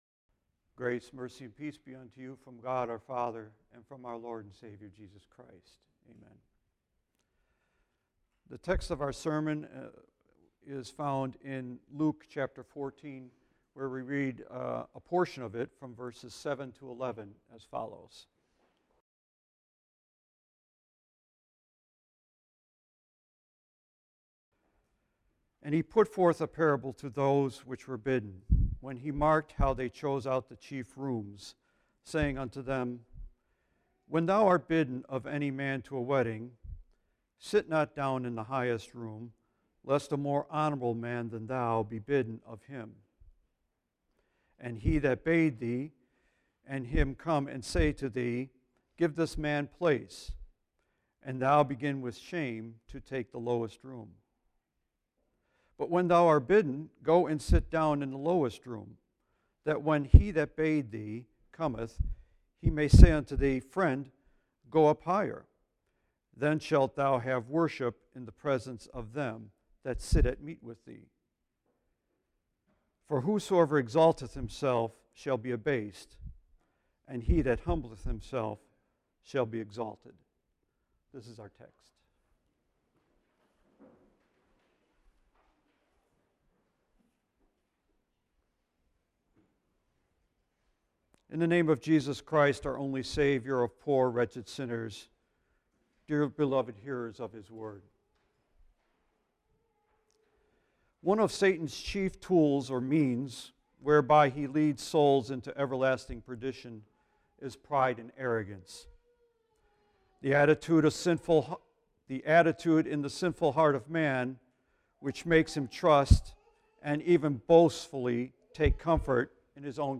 10-13-19 Why We Christians Should Practice Humility in Our Lives I. Because sinful pride and arrogance bring disastrous consequences. II. Because true Christian humility is rewarded with gracious blessings from God Himself. Text: Luke 14:1-11 This text will be replaced by the JW Player Right click & select 'Save link as...' to download entire Sermon video Right click & select 'Save link as...' to download entire Sermon audio